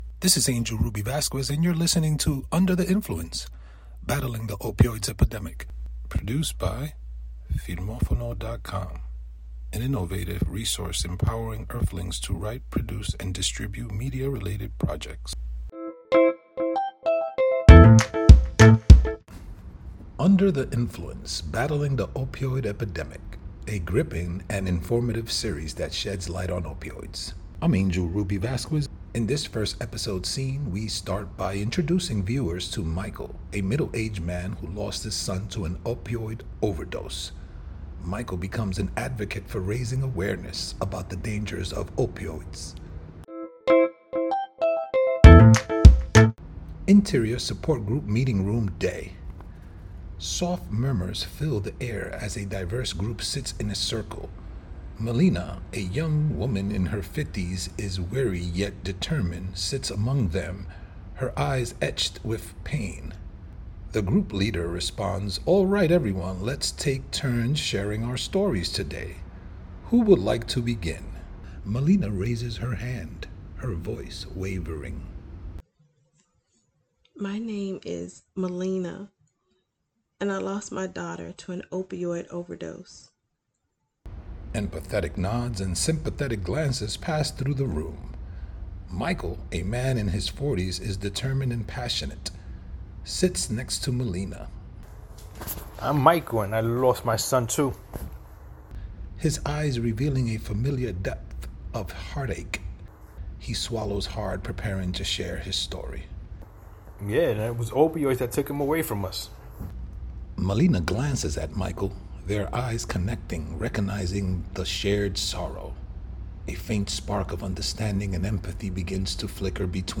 Description: “Under the Influence: Battling the Opioid Epidemic” is a multiple narrative podcast. Each episode has multiple stories told in segments.